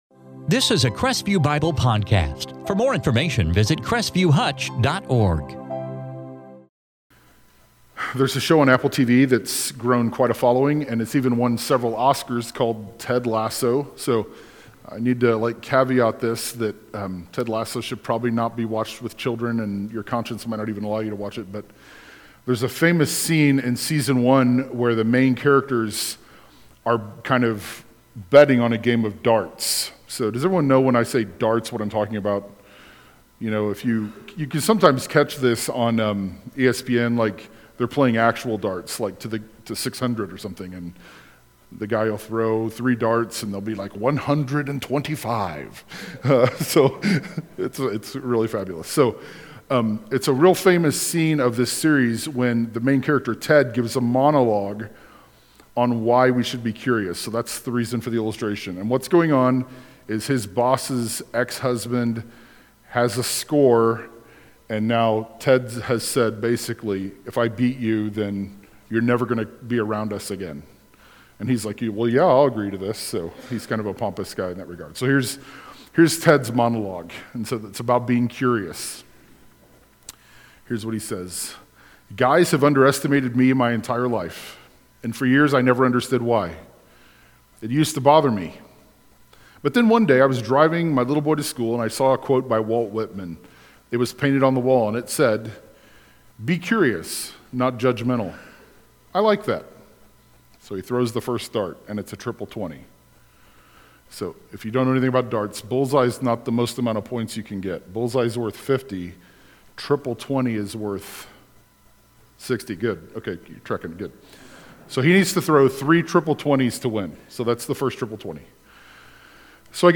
Church